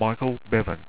Pronounced
MIKAL BEVEN